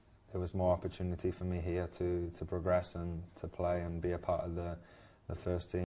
males/2.wav